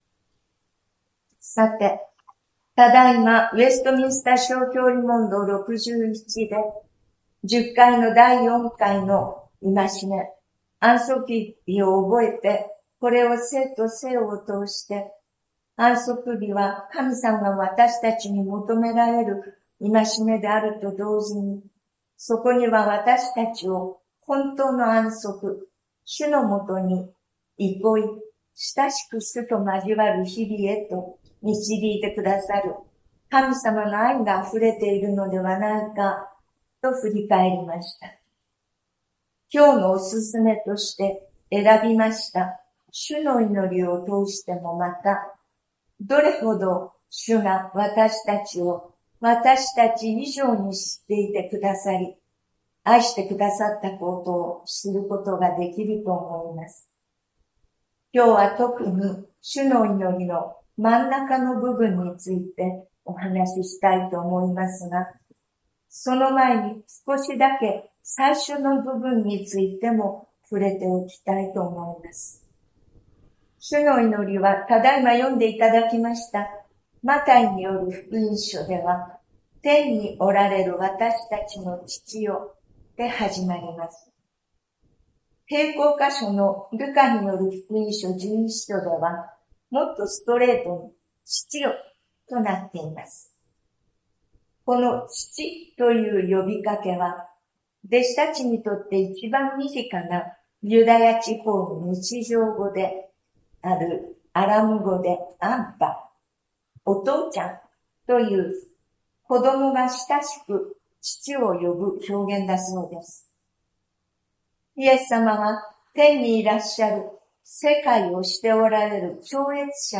日曜朝の礼拝
礼拝説教を録音した音声ファイルを公開しています。